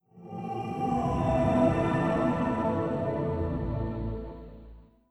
OS3 Warp 3.0 Shutdown.wav